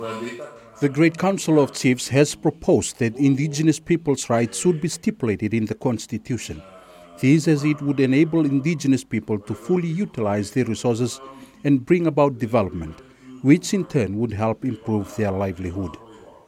Prime Minister Sitiveni Rabuka at the Macuata Provincial Council meeting